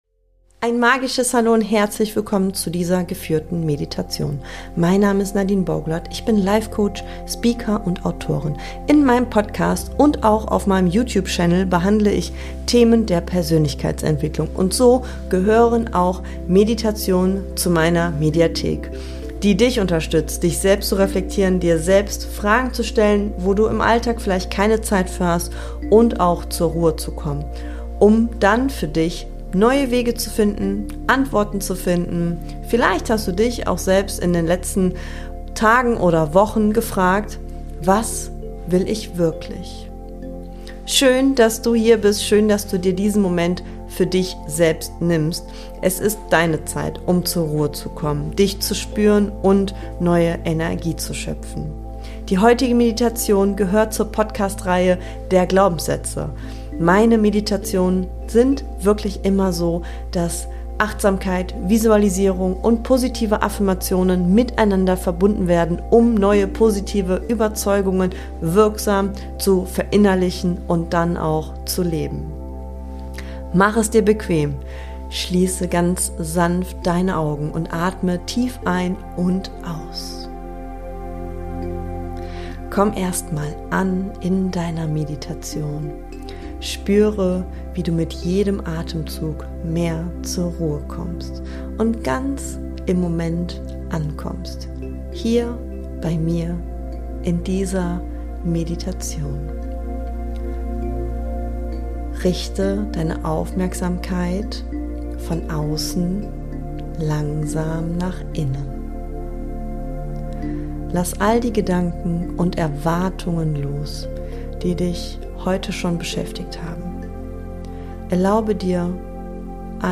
Geführte Meditation für innere Freiheit | Glaubenssätze (Part 6/6) ~ Boost your Mind to bright your Life Podcast